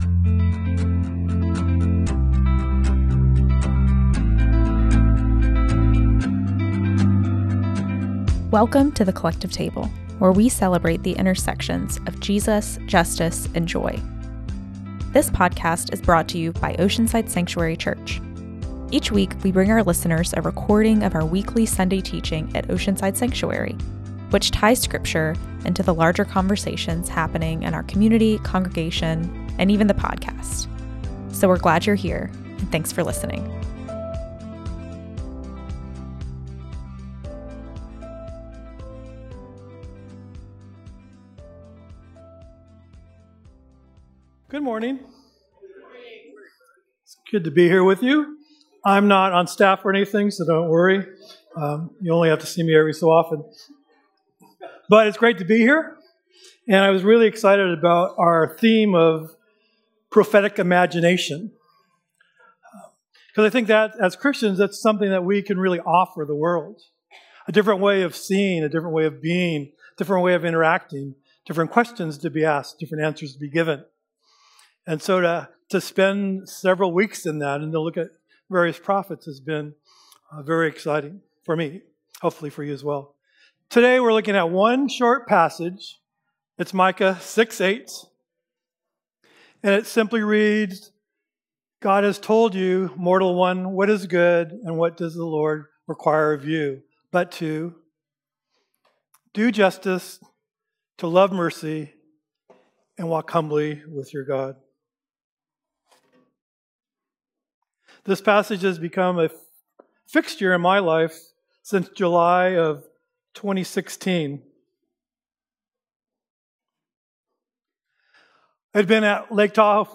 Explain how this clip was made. Each week, we bring our listeners a recording of our weekly Sunday teaching at Oceanside Sanctuary, which ties scripture into the larger conversations happening in our community, congreg…